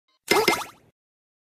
Anime Confused Blink Sound Button - Free Download & Play